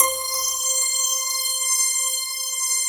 FLNGHARPC5-L.wav